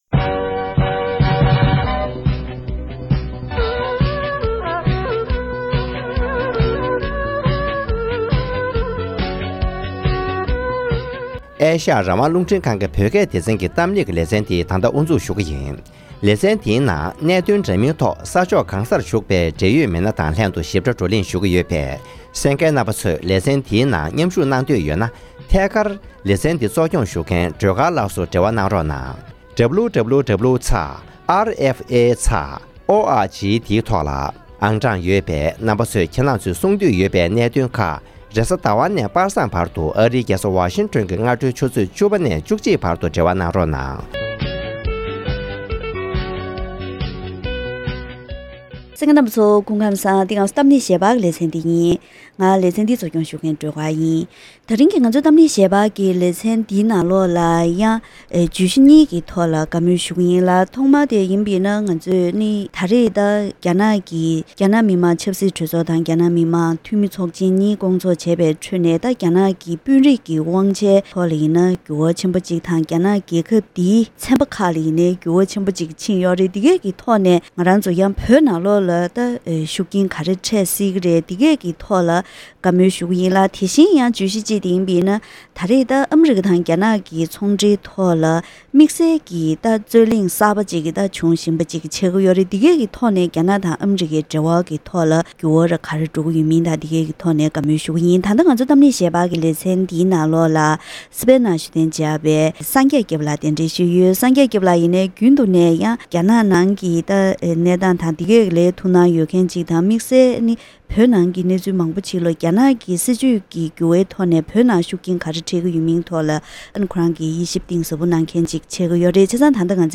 གཏམ་གླེང་ཞལ་པར་ལེ་ཚན་ནང་ཞི་ཅིང་ཕིང་གི་འགོ་ཁྲིད་པའི་རྒྱ་ནག་གི་དཔོན་རིགས་དཔུང་ཁག་གསར་པའི་ནང་དམིགས་བསལ་བོད་ཁུལ་དུ་ལས་ཀ་ལོ་མང་བྱ་མྱོང་ཡོད་པའི་མི་སྣ་གསུམ་ཡོད་པའི་སྐོར་དང་བོད་ཐོག་འཛིན་པའི་སྲིད་ཇུས་ལ་ཤུགས་རྐྱེན་ཇི་ཡོད་དང་། དེ་མིན་ཨ་རི་དང་རྒྱ་ནག་གི་ཚོང་འབྲེལ་རྩོད་གླེང་སོགས་ཀྱི་སྐོར་ལ་གླེང་མོལ་ཞུས་པ་ཞིག་གསན་རོགས་གནང་།